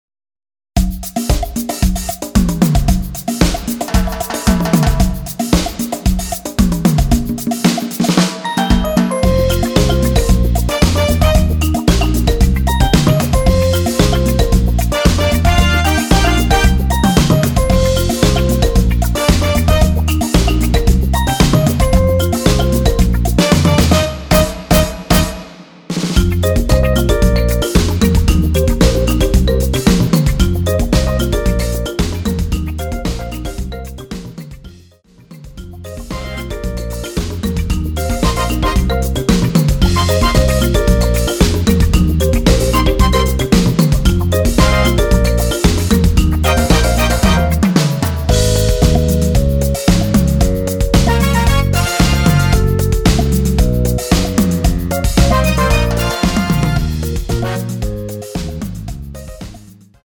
원키에서 (-1)내린 MR 입니다.(미리듣기 참조)
Eb
앞부분30초, 뒷부분30초씩 편집해서 올려 드리고 있습니다.